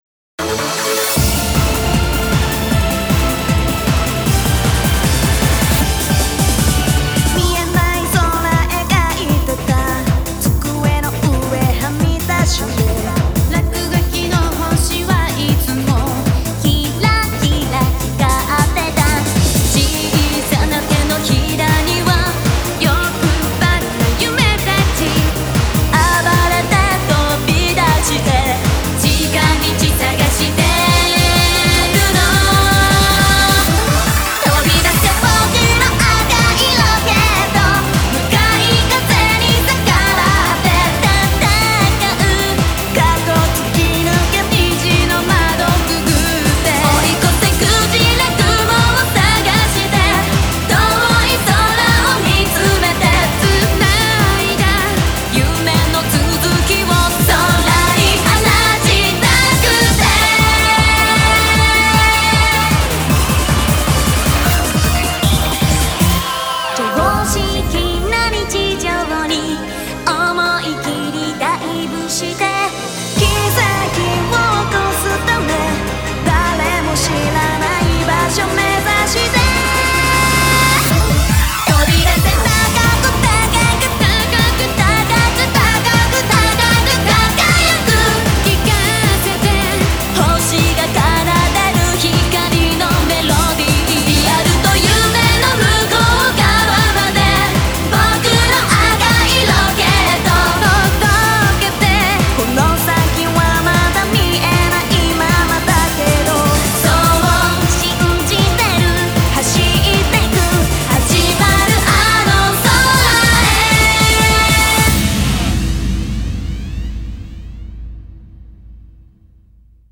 BPM155
Audio QualityPerfect (High Quality)
J-POP